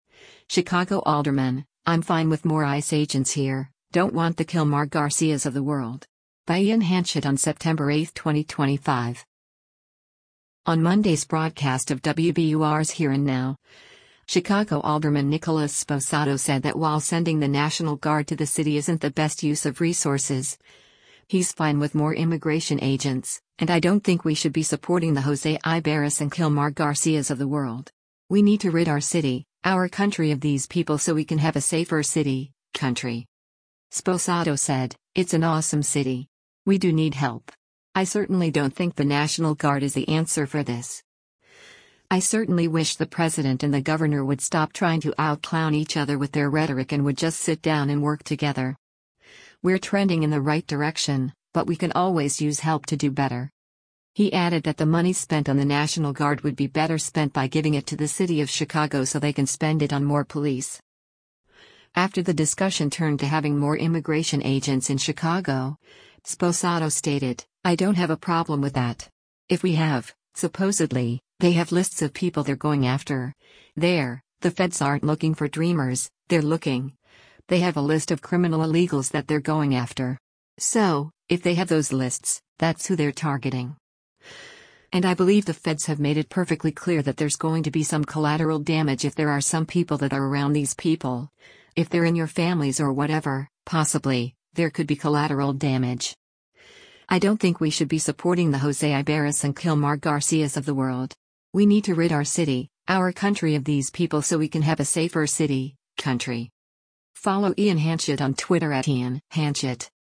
On Monday’s broadcast of WBUR‘s “Here and Now,” Chicago Alderman Nicholas Sposato said that while sending the National Guard to the city isn’t the best use of resources, he’s fine with more immigration agents, and “I don’t think we should be supporting the Jose Ibarras and Kilmar Garcias of the world. We need to rid our city, our country of these people so we can have a safer city, country.”